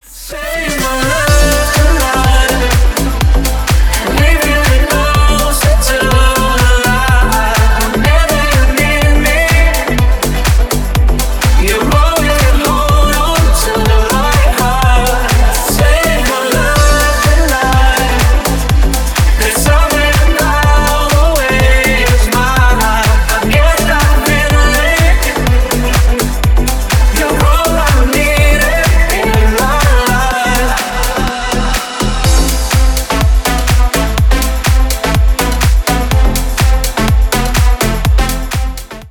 дуэт , клубные
поп , ремиксы